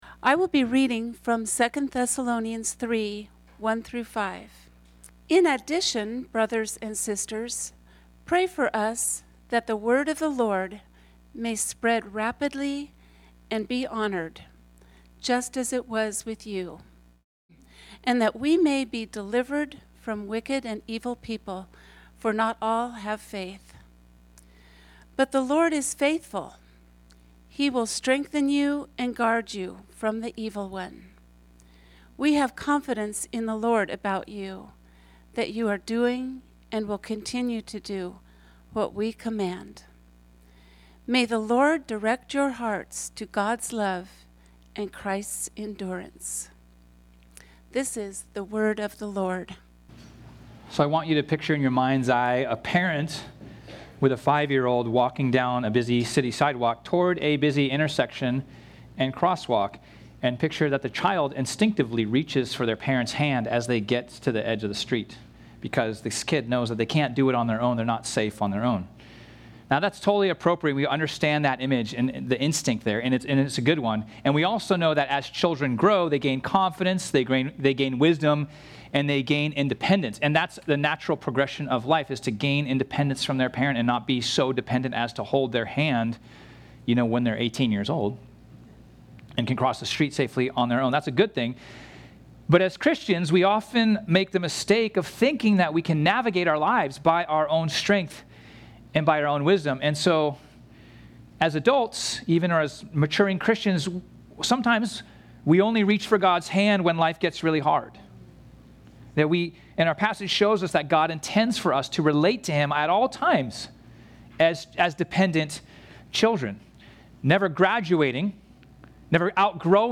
This sermon was originally preached on Sunday, September 21, 2025.